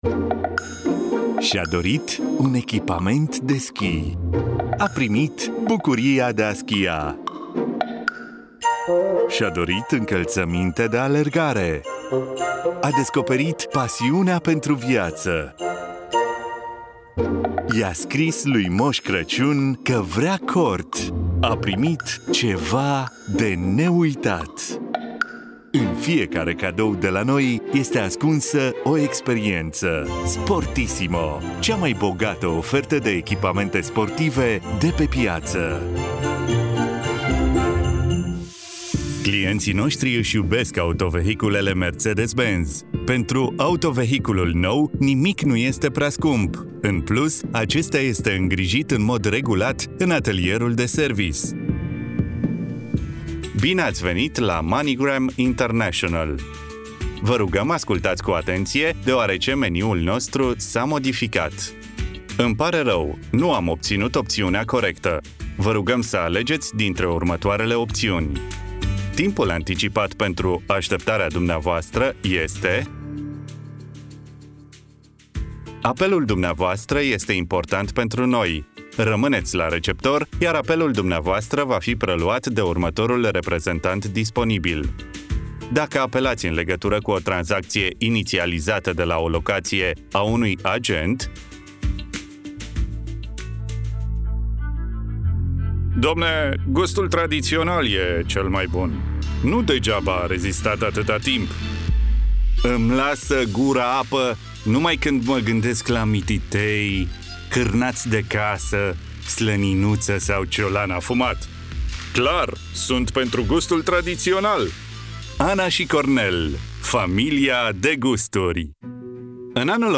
Experienced Romanian male, native, voice talent
Romanian voice over talent - Reel
Middle Aged